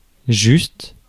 Ääntäminen
France: IPA: [ʒyst]